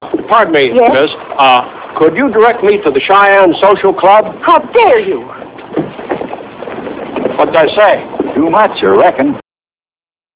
Miss.real audio-11kbJohn asking directions to the club!